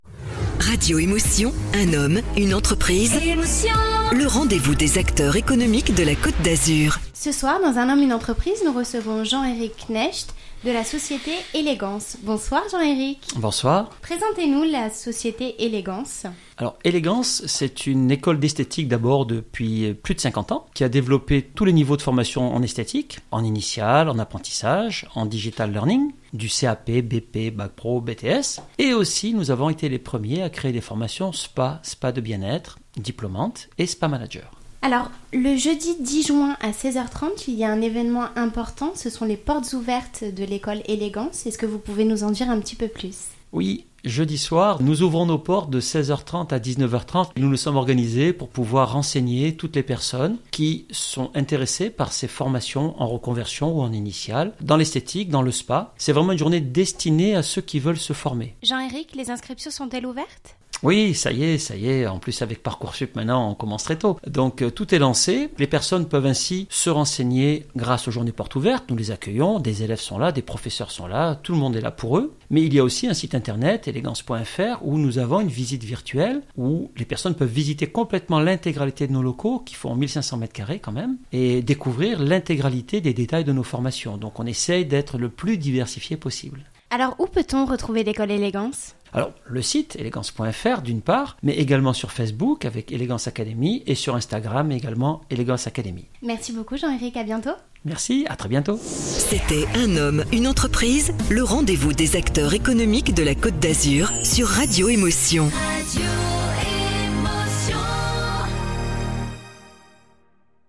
Nous remercions encore une fois Radio Emotion de nous avoir invité dans leur locaux.